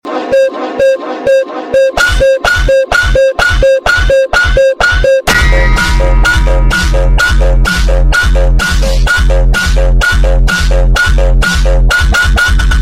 break the house2 phonk Meme Sound Effect
break the house2 phonk.mp3